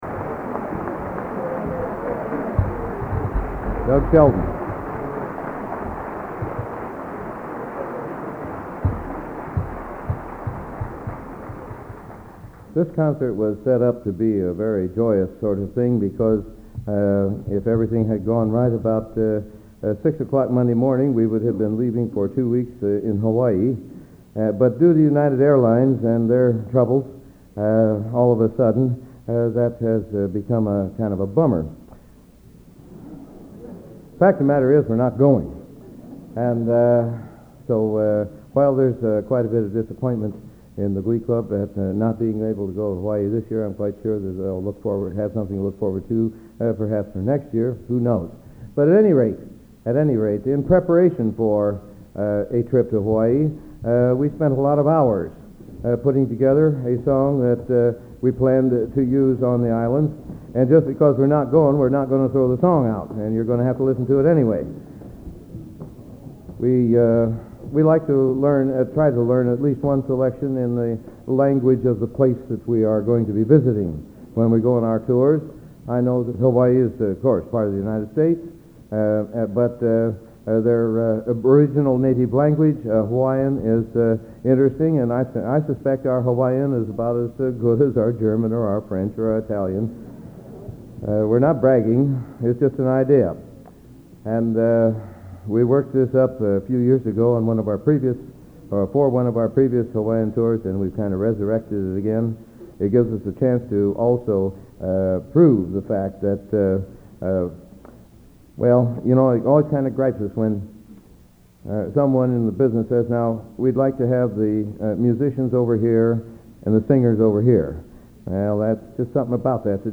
Location: West Lafayette, Indiana
Genre: | Type: Director intros, emceeing